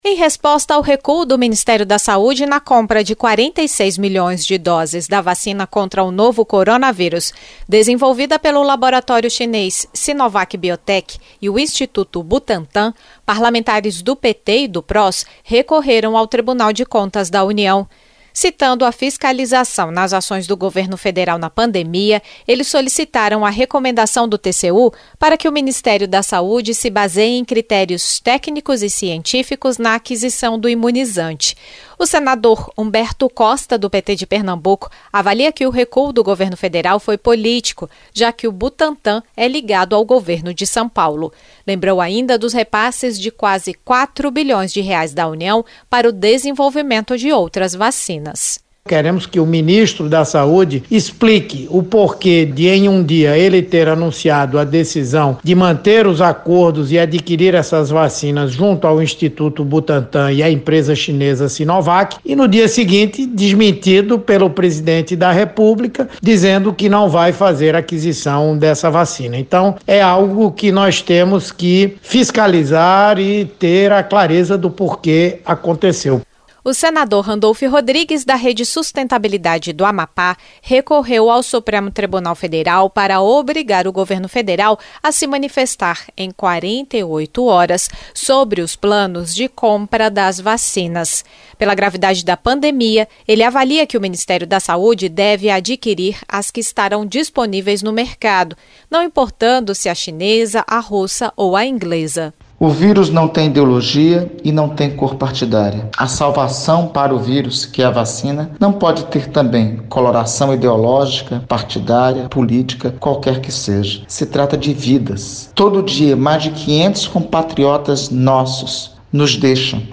O senador Humberto Costa (PT-PE) destacou as declarações políticas de Bolsonaro.